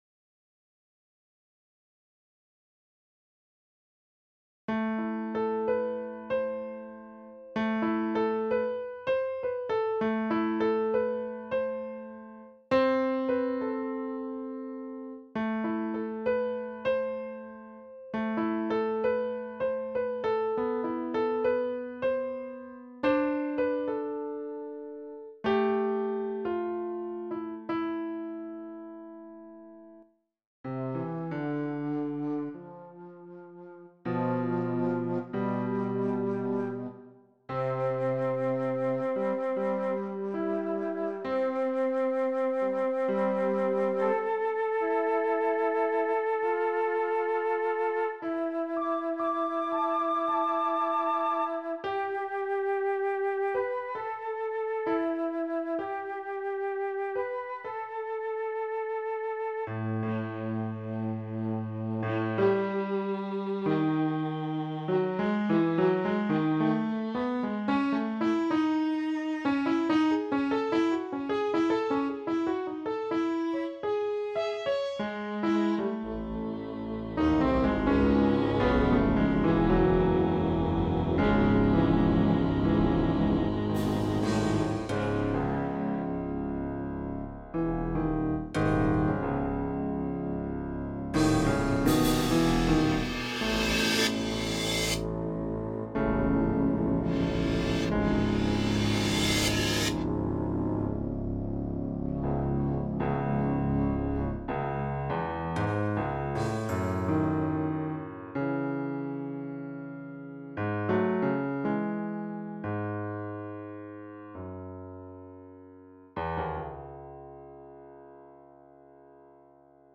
Posted in Classical, Other Comments Off on